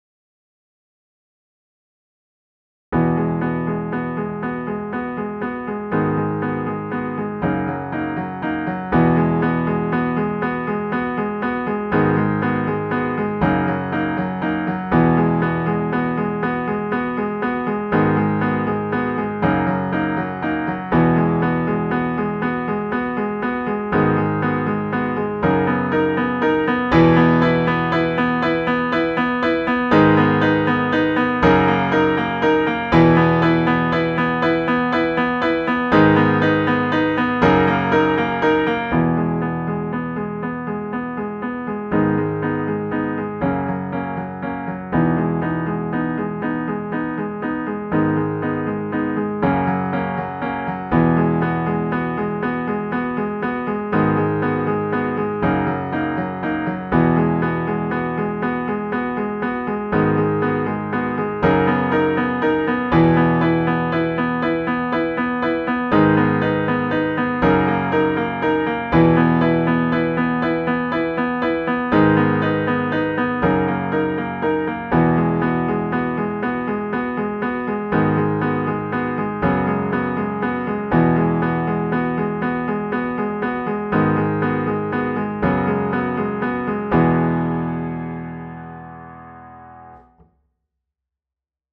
ریتم: 6/4
تمپو: 120
گام: B Major